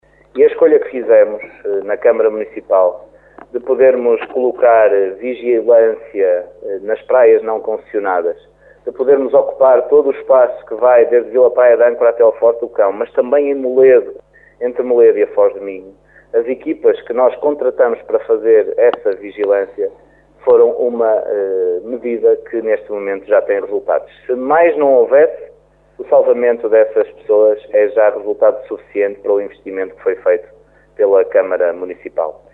Embora reconhecendo que nem tudo é perfeito, o presidente da Câmara diz estar satisfeito com o que tem acontecido nas praias concelhias no decorrer da época balnear.